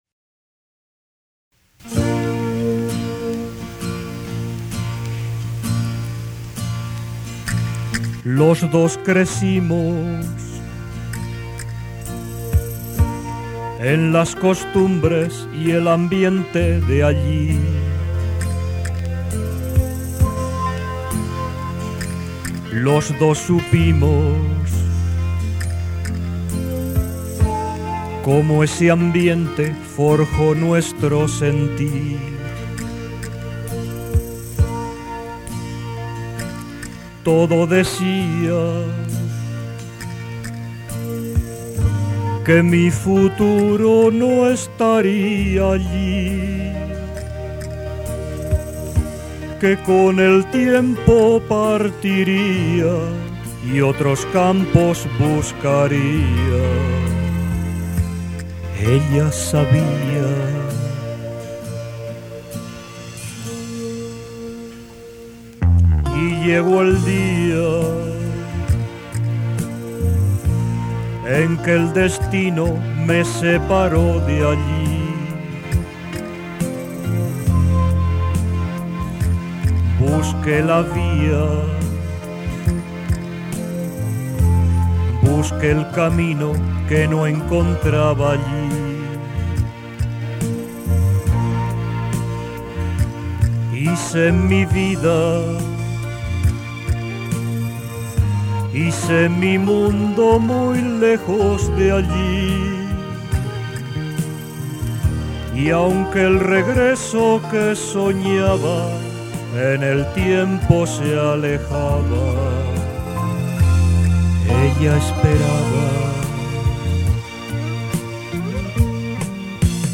• Título de la melodía instrumental: “Mama Leone”, en interpretación de Anthony Ventura.